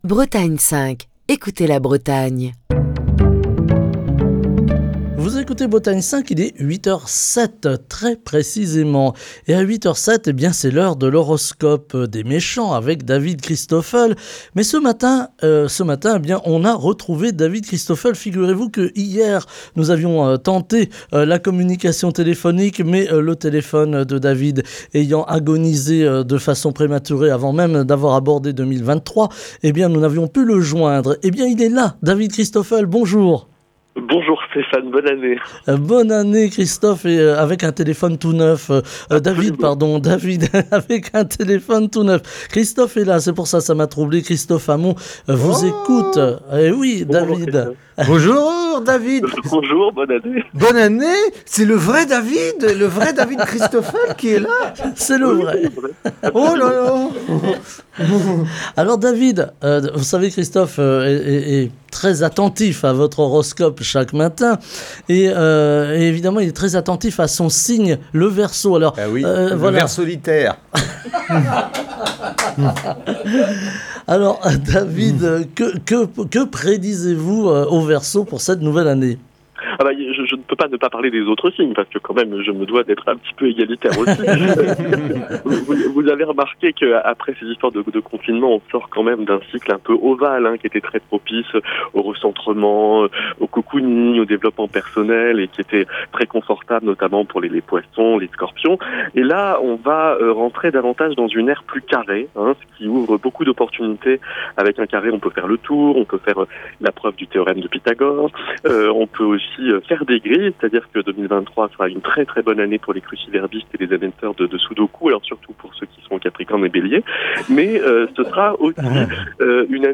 Chronique du 4 janvier 2023.